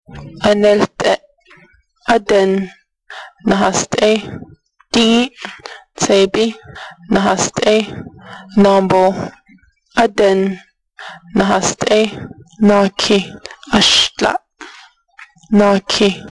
29 January 2012 at 8:27 am Well the repetition and slow speaking suggests it’s from a language course of some sort, I got that far…
30 January 2012 at 2:34 am It seems to be mainly Navajo number words, possibly a telephone number: 2, 4, 5, 8, 9 are in there.